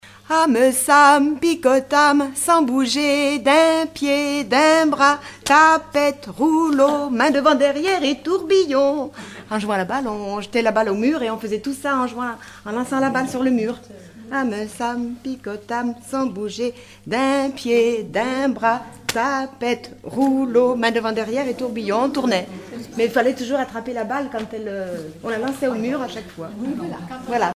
Enfantines - rondes et jeux
enfantine : jeu de balle
Regroupement de chanteurs du canton
Pièce musicale inédite